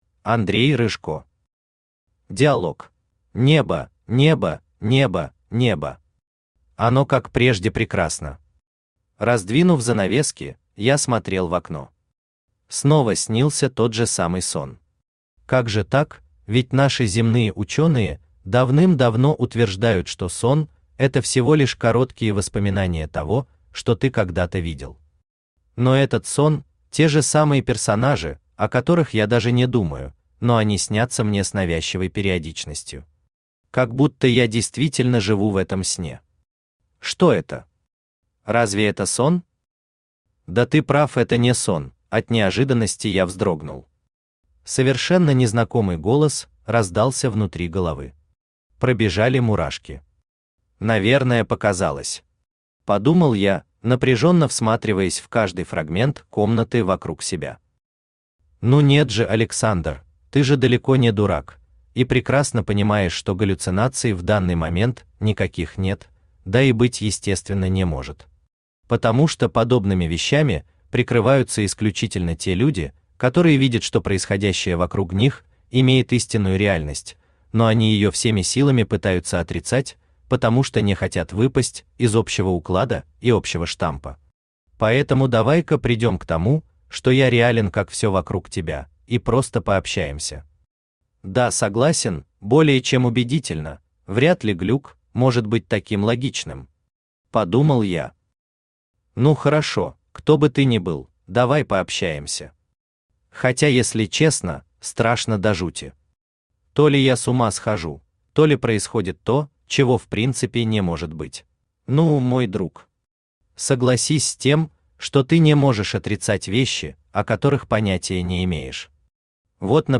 Аудиокнига Диалог | Библиотека аудиокниг
Aудиокнига Диалог Автор Андрей Николаевич Рыжко Читает аудиокнигу Авточтец ЛитРес.